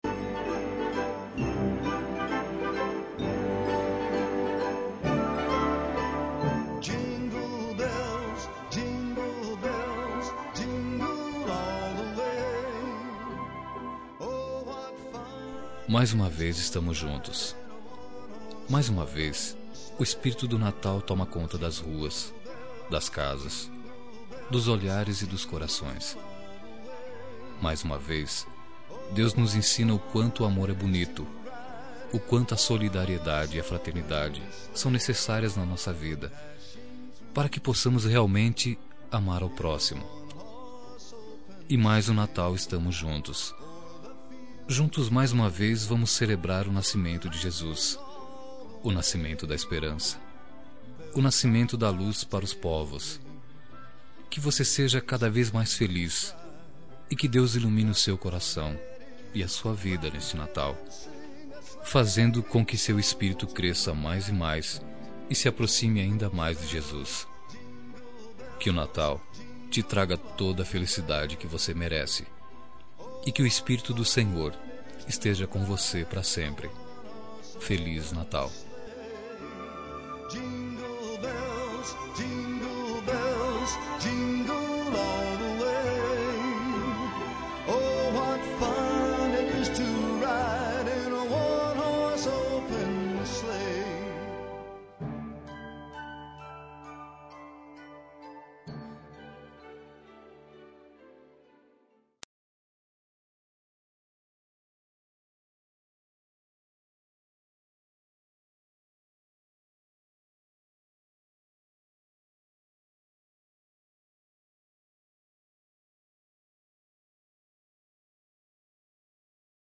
Natal Romântica – Voz Masculina – Cód: 34794